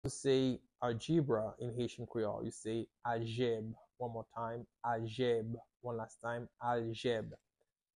How to say "Algebra" in Haitian Creole - "Aljèb" pronunciation by a native Haitian Creole tutor
“Aljèb” Pronunciation in Haitian Creole by a native Haitian can be heard in the audio here or in the video below:
How-to-say-Algebra-in-Haitian-Creole-Aljeb-pronunciation-by-a-native-Haitian-Creole-tutor.mp3